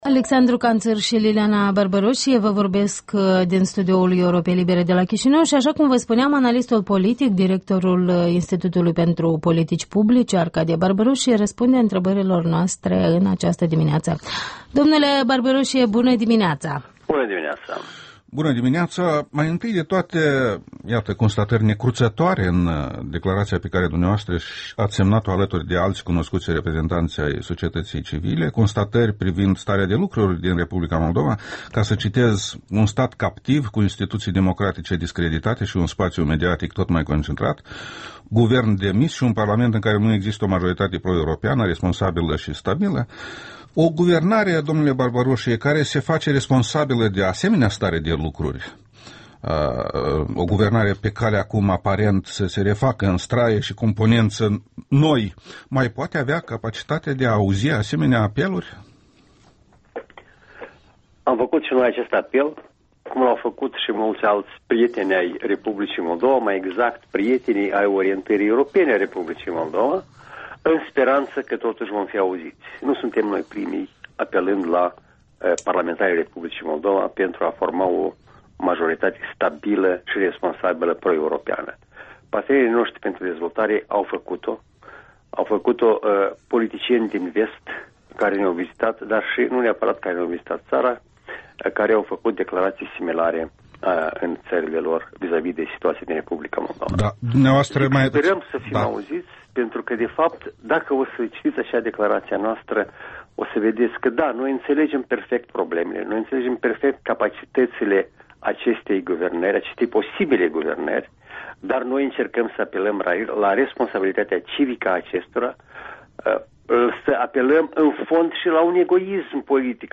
Interviul matinal la Europa Liberă